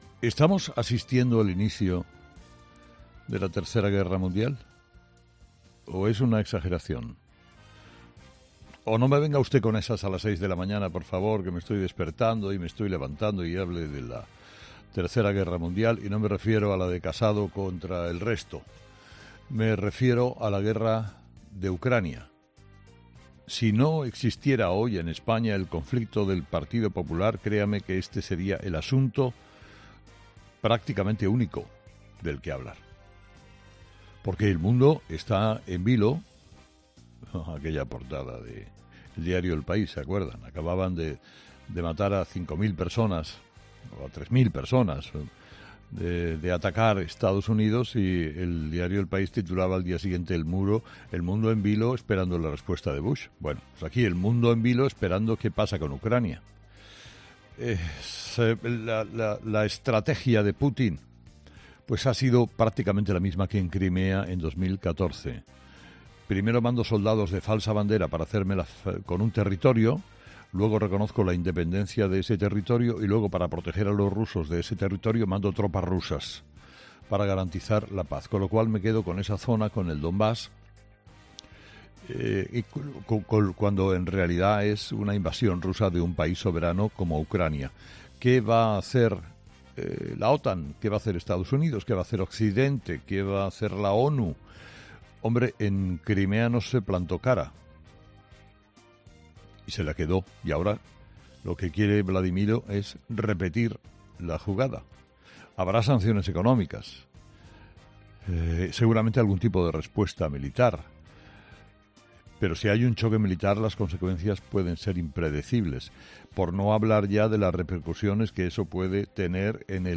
Carlos Herrera, director y presentador de 'Herrera en COPE', ha comenzado el programa de este martes analizando las principales claves de la jornada, que pasan por la situación en Ucrania y por la última hora sobre la crisis interna que se vive en el Partido Popular.